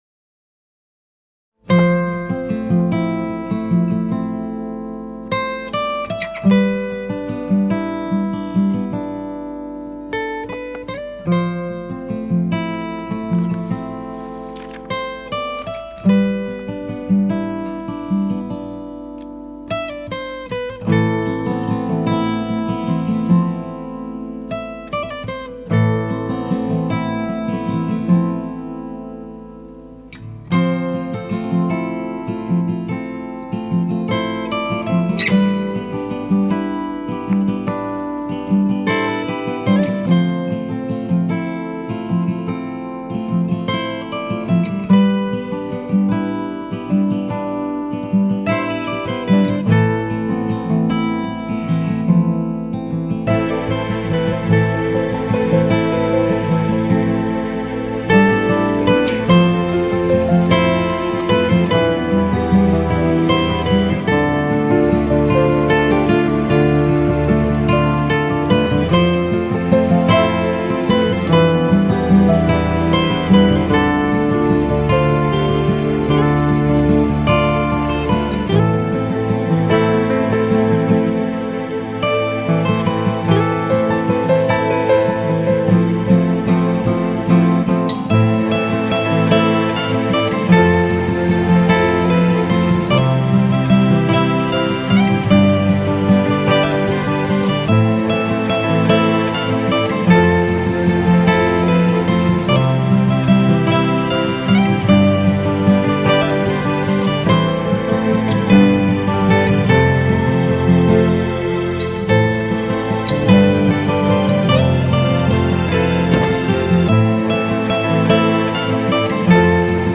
Gitar Versiyonu
duygusal huzurlu rahatlatıcı fon müziği.